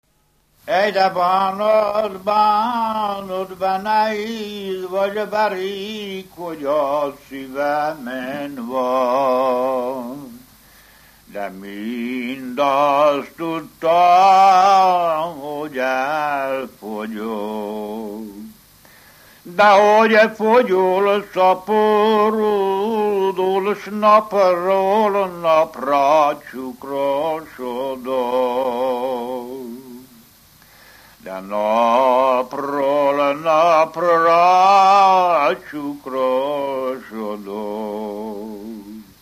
Erdély - Csík vm. - Gyimesközéplok
Pszalmodizáló stílusú dallamok